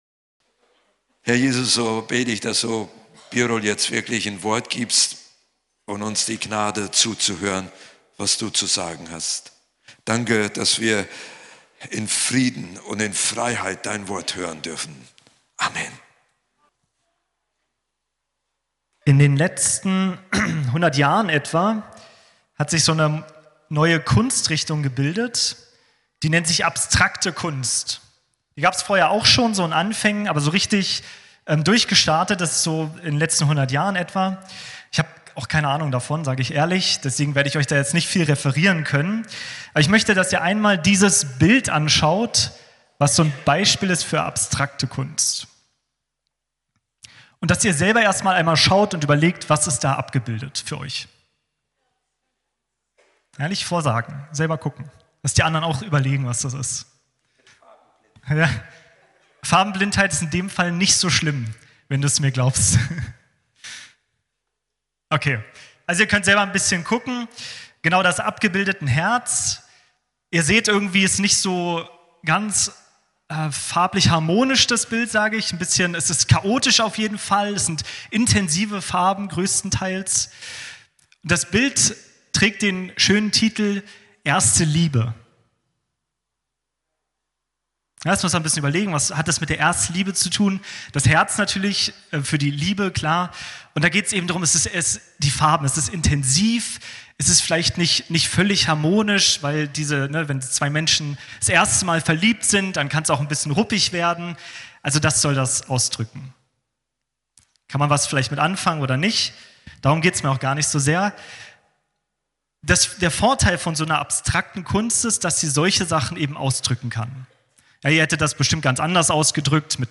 Gottesdienst vom 12.01.2025